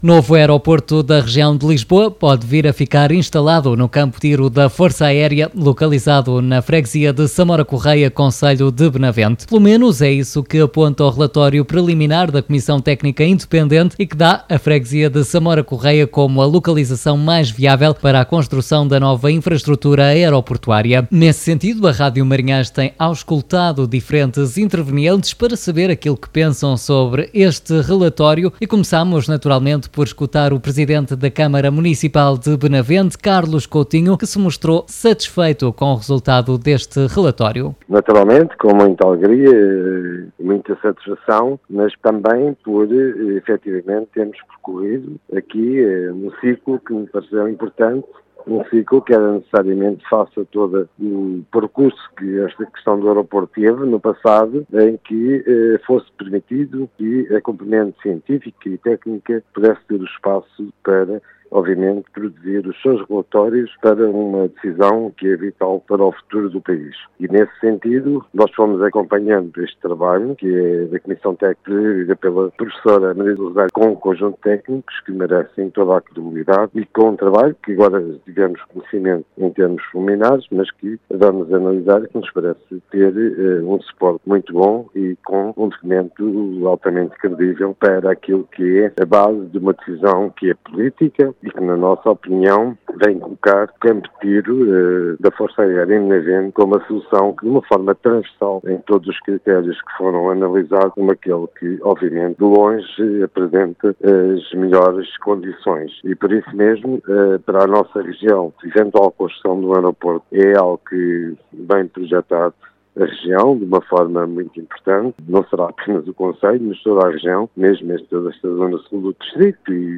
A Rádio Marinhais entrevistou, até ao momento, diferentes intervenientes sobre o conteúdo do relatório preliminar da CTI. Para o Presidente da Câmara Municipal de Benavente, Carlos Coutinho, o resultado do estudo efetuado pela CTI é um motivo de satisfação, enaltecendo o espaço que as componentes científicas e técnicas tiveram para produzir o seu trabalho.